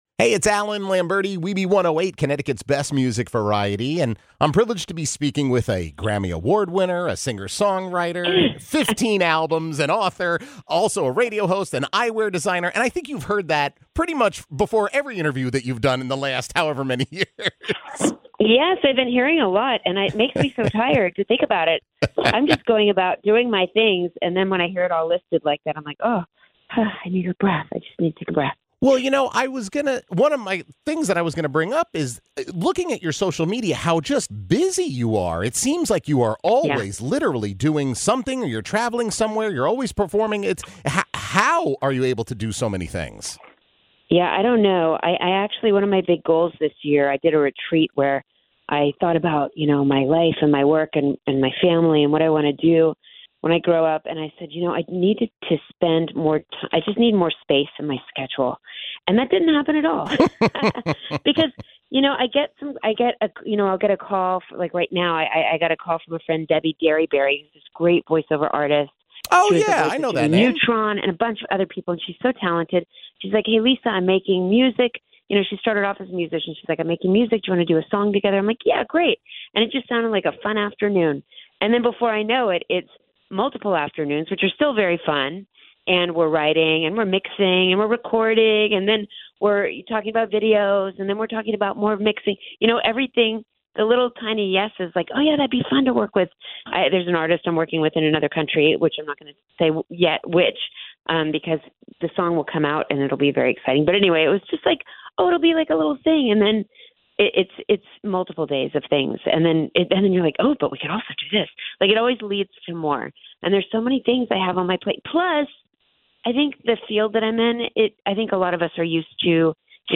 Singer-songwriter Lisa Loeb chats